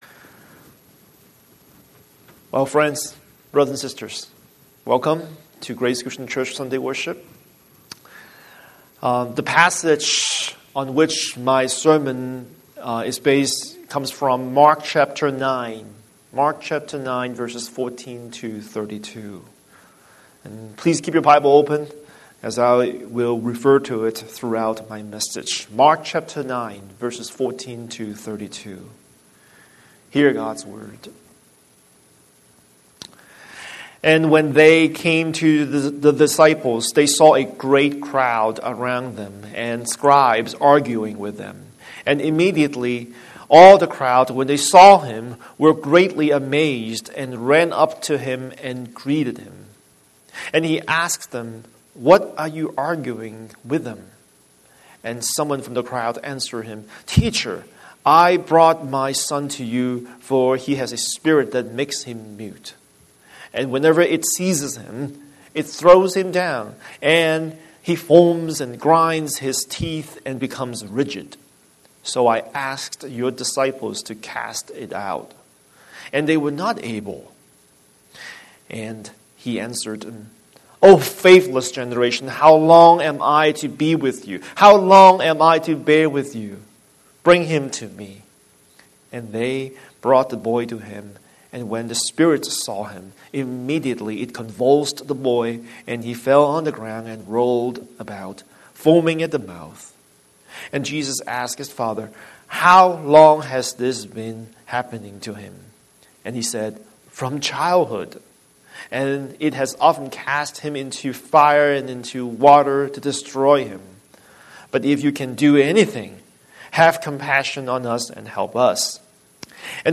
Scripture: Mark 9:14-9:32 Series: Sunday Sermon